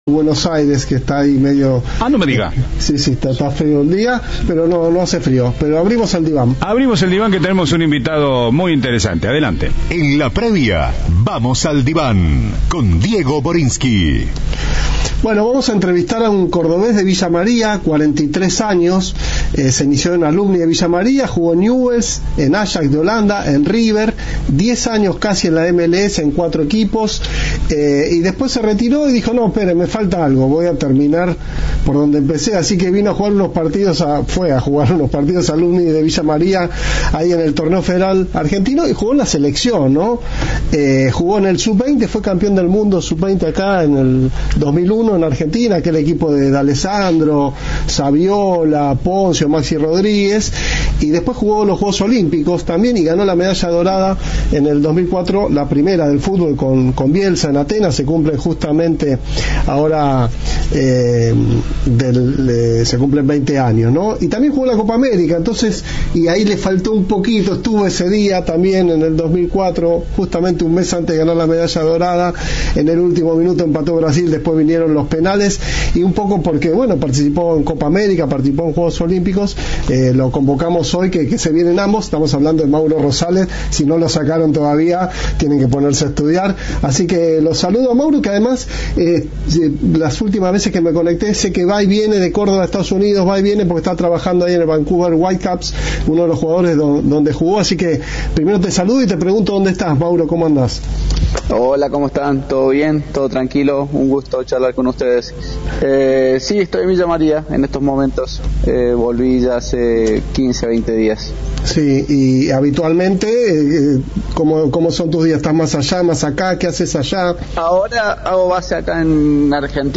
El villamariense, medallista de oro en los Olímpicos de 2004, trabaja actualmente como scouting en un club de la liga estadounidense. En dialogo con Cadena 3 habló sobre el boom generado por Messi y su época como jugador.